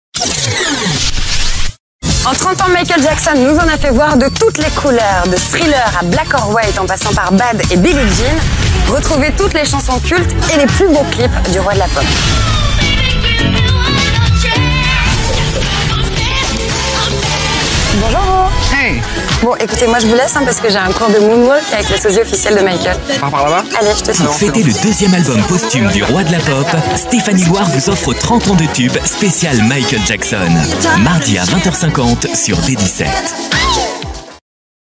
D17 speciale Michael Jackson - Comédien voix off
Genre : voix off.